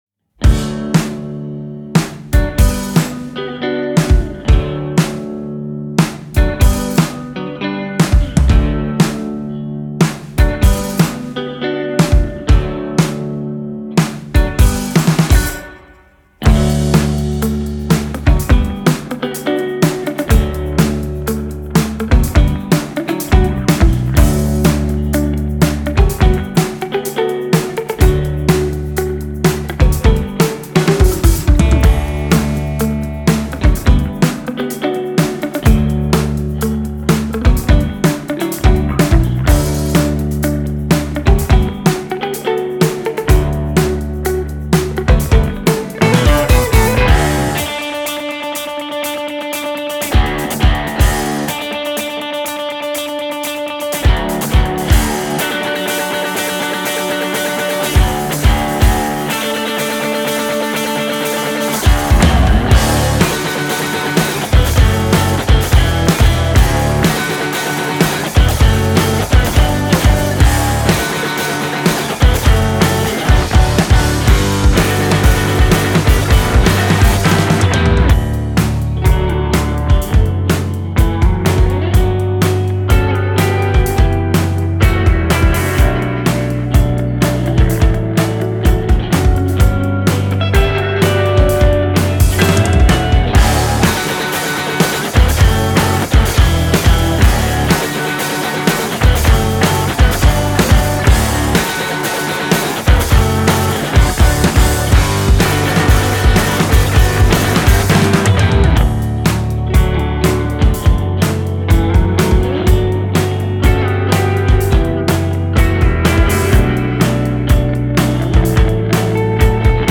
Math-Rock